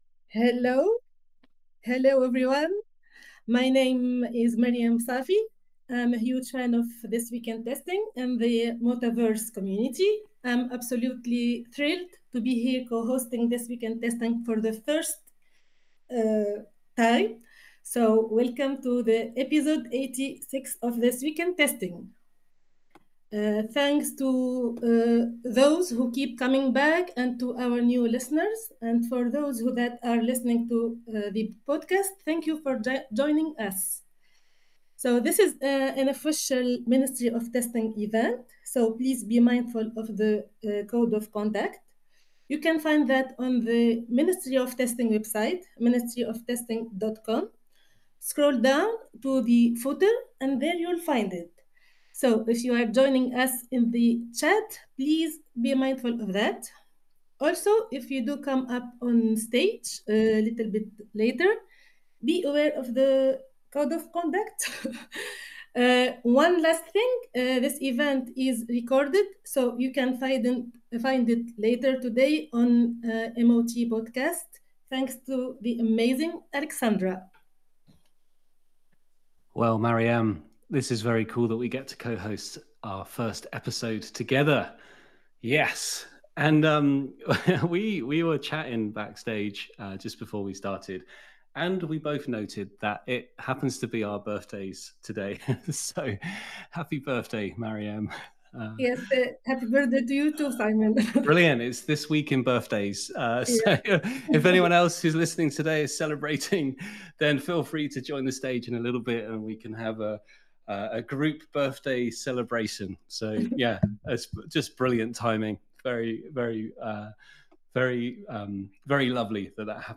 🎙 Tune in for thoughtful conversations, testing news, and community insights covering everything from QA trends to quality engineering practices.
Join the live session every Friday or catch up on past episodes wherever you get your podcasts.